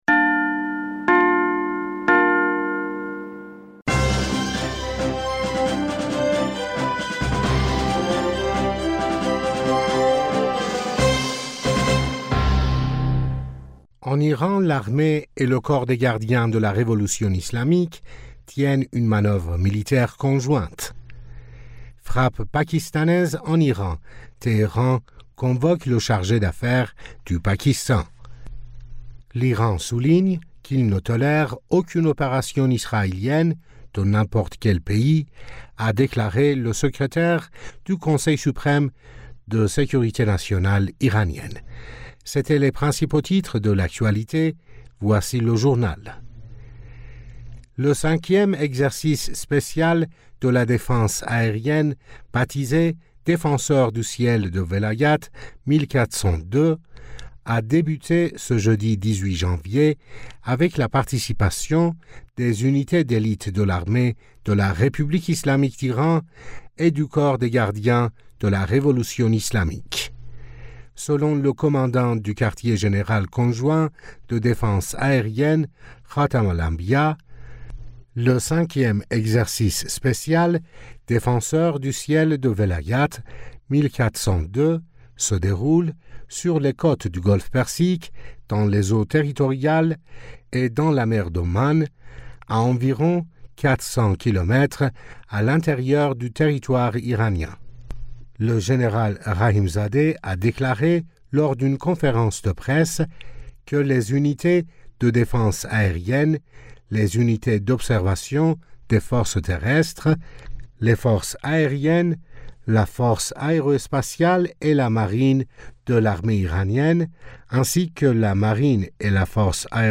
Bulletin d'information du 18 Janvier 2024